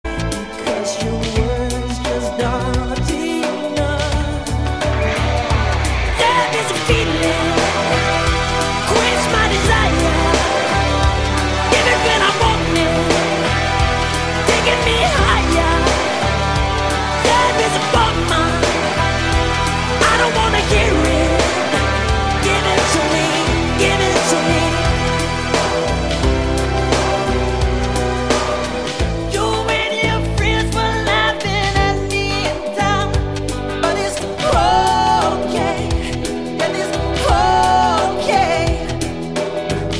pop rock